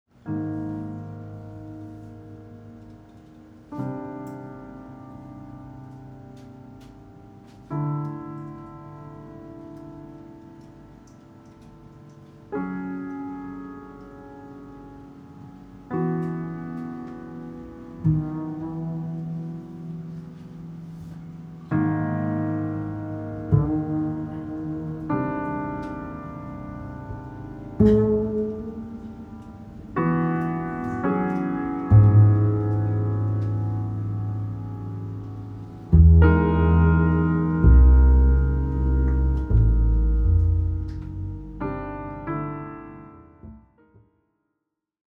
Recorded on Oct. 9.2025 at Jazz Bar Nardis